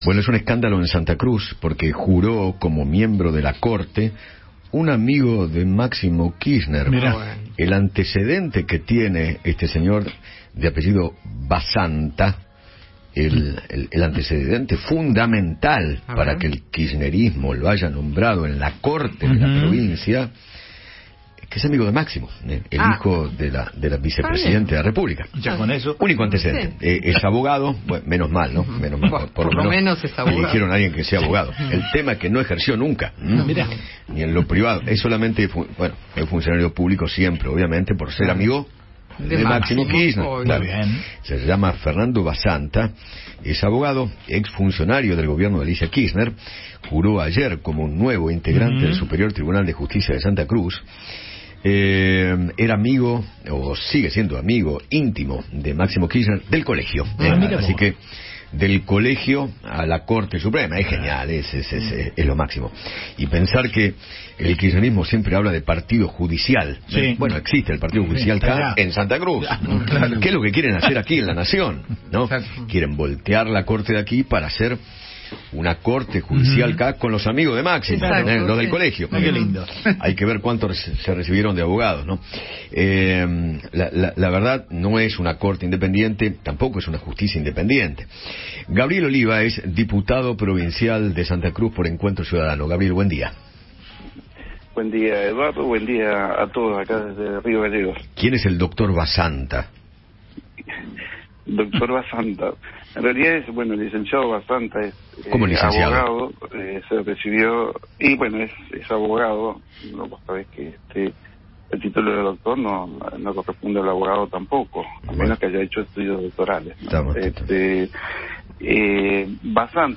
Gabriel Oliva, diputado provincial de Santa Cruz por Encuentro Ciudadano, charló con Eduardo Feinmann sobre la designación de un amigo de Máximo Kirchner en la Corte Suprema de aquella provincia.